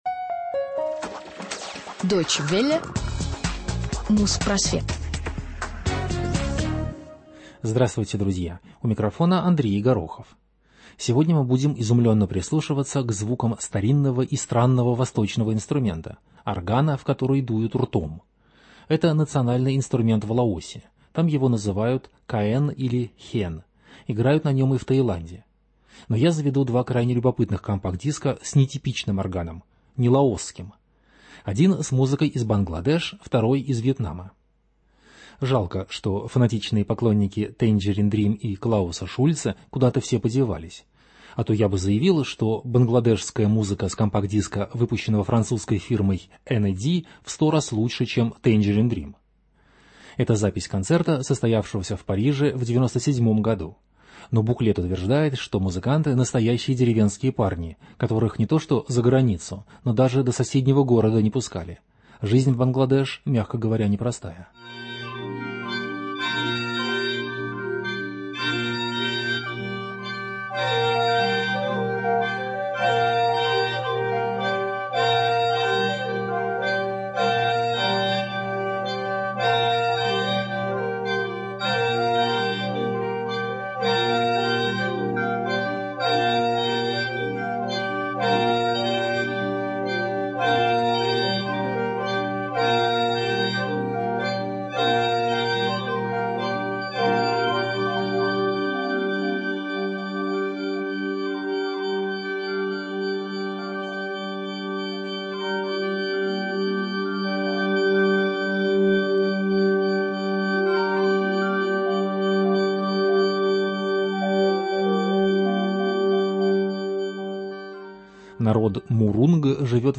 Музпросвет 306 от 21 июня 2008 года - Органная музыка Бангладеш и Вьетнама | Радиоархив
Orgues-a-bouche.